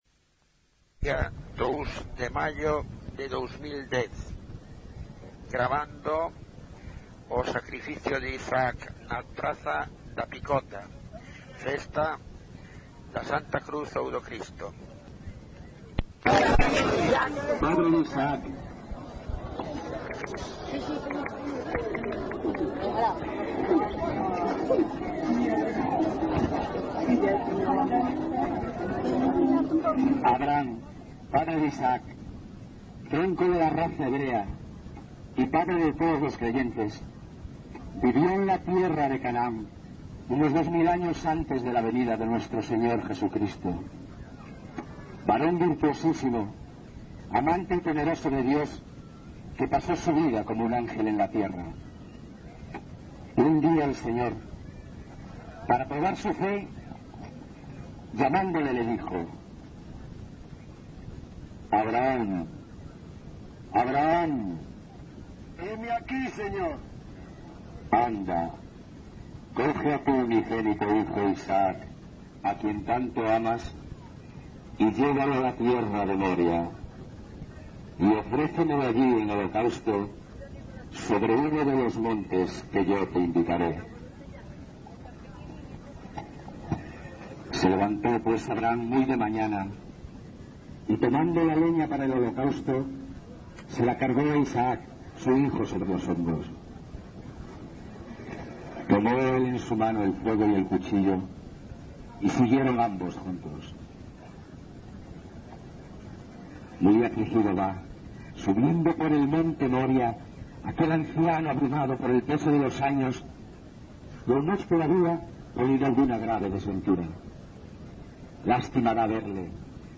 [thumbnail of Son do sacrificio de Isaac e debate entre o mouro e o cristián na Festa do Cristo] Audio (Son do sacrificio de Isaac e debate entre o mouro e o cristián na Festa do Cristo)
Os dous primeiros levan espadas mentres que os restantes usan castañolas para acompañar o ritmo da danza.
Os personaxes bíblicos antes citados interpretan os seus papeis en distintos momentos da procesión, e a danza actúa reiteradamente ao ritmo dunha melodía interpretada por gaiteiros, bombo e tamboril. Ao final do percorrido, xa á porta da igrexa parroquial, é cando os danzantes forman en dúas ringleiras de a tres e os dous primeiros, que fan respectivamente de mouro e cristián, representan un breve diálogo en lingua castelá—o que indica a súa orixe foránea ou culta-- e acaban loitando, con vitoria do cristián e arrepentimento do mouro vencido.
Lugar de compilación: Laza - San Xoán de Laza - Laza
Soporte orixinal: Casete